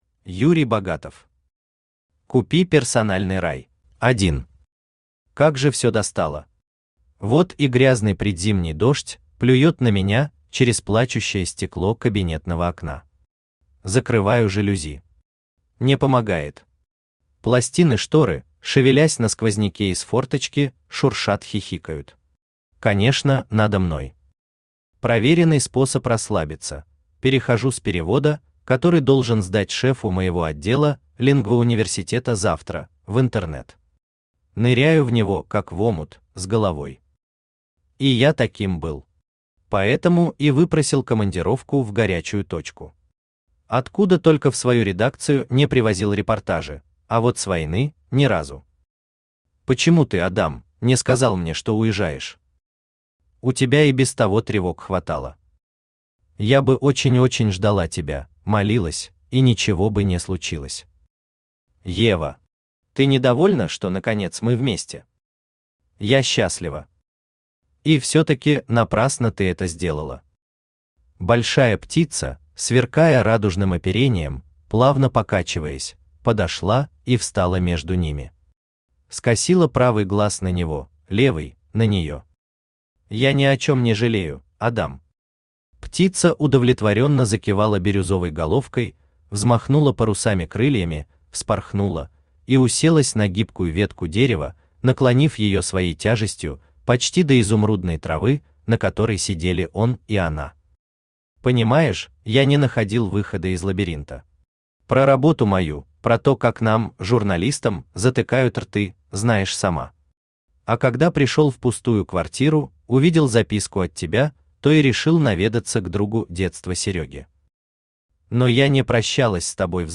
Аудиокнига Купи персональный рай!
Автор Юрий Анатольевич Богатов Читает аудиокнигу Авточтец ЛитРес.